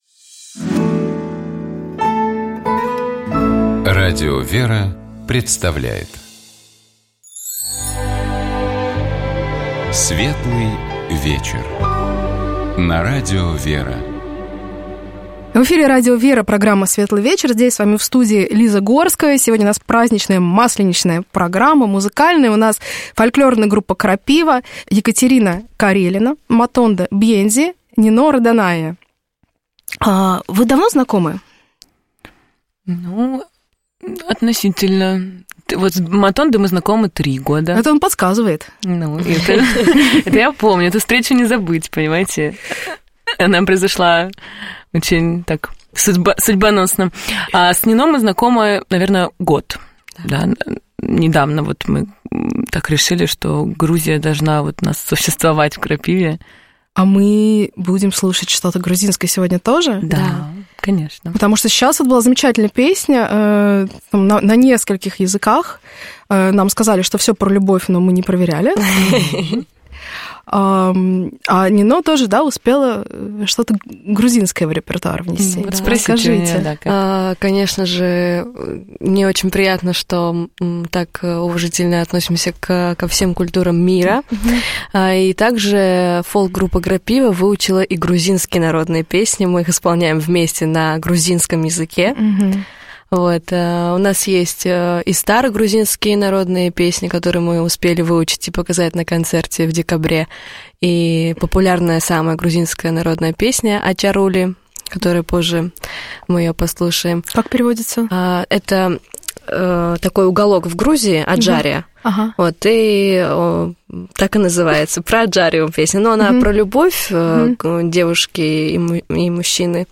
В разгар Масленицы мы говорили о русском музыкальном фольклоре, о том, что привлекает в нем представителей других культур, и конечно, слушали песни в исполнении наших гостей.